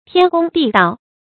天公地道 注音： ㄊㄧㄢ ㄍㄨㄙ ㄉㄧˋ ㄉㄠˋ 讀音讀法： 意思解釋： 像天地一樣公道。形容非常公平合理。